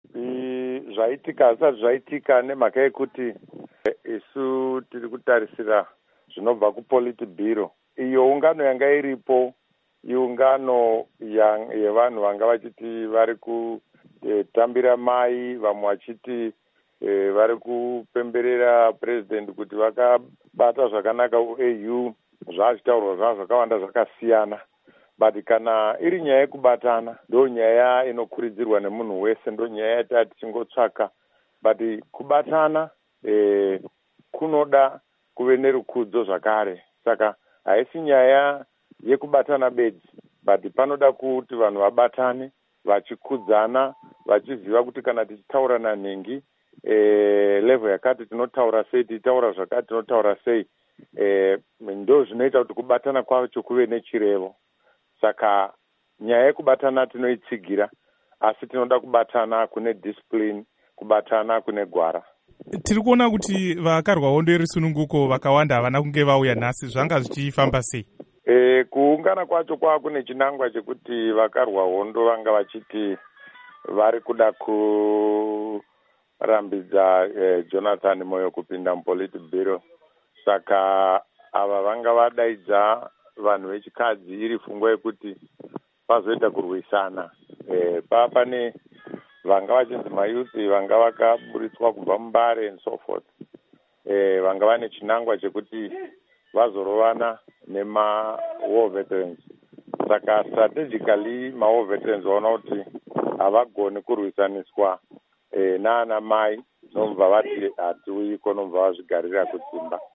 Hurukuro naVaVictor Matemadanda